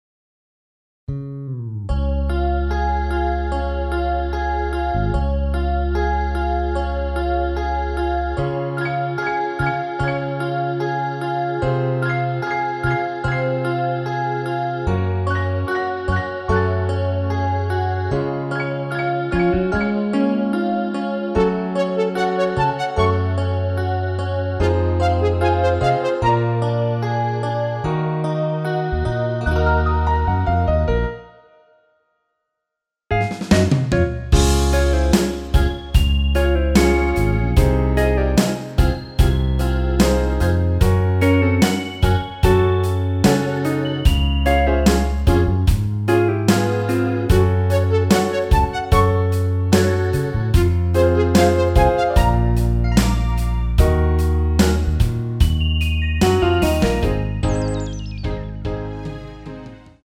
엔딩이 페이드 아웃이라 라이브하기 좋게 엔딩을 만들어 놓았습니다.
원키에서(+3)올린 MR입니다.
여성분들이 부르실수 있는 키로 제작 하였습니다.
Db
앞부분30초, 뒷부분30초씩 편집해서 올려 드리고 있습니다.